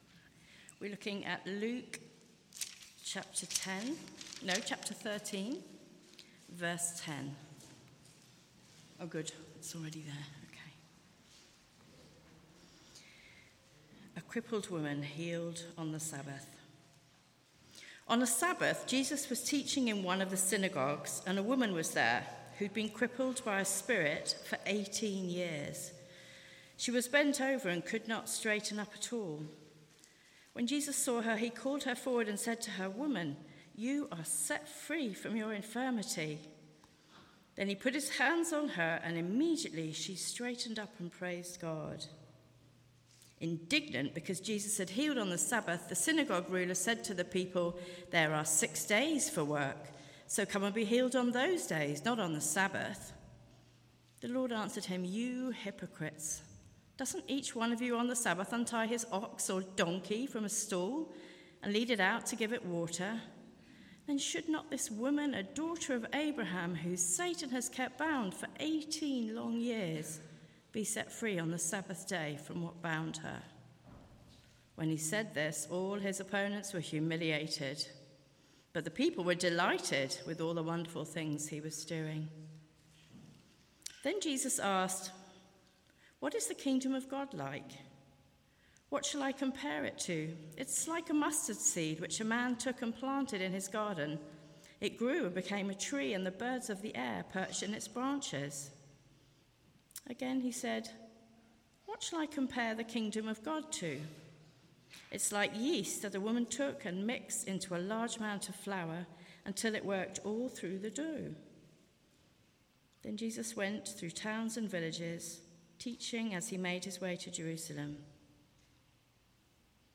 Media for Sunday Service on Sun 15th Jun 2025 10:30
Series: Teachings of Jesus Theme: Introduction and Kingdom of God Sermon